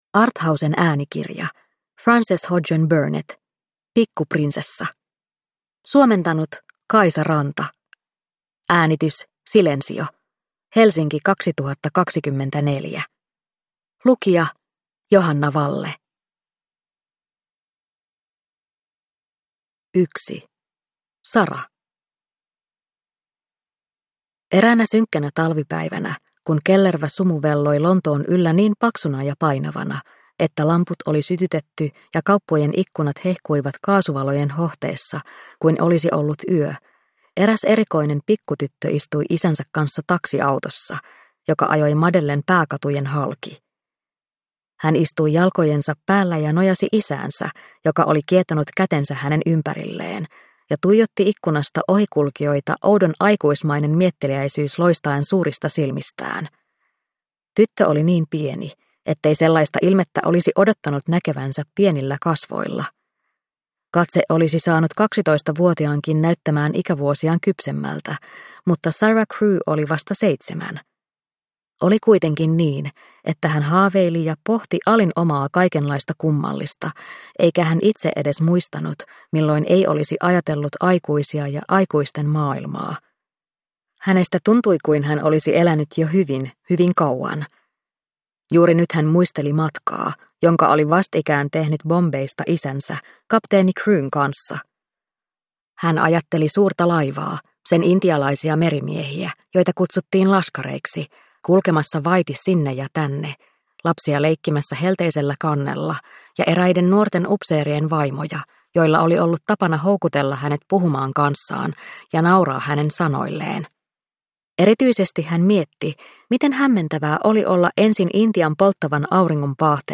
Pikku prinsessa – Ljudbok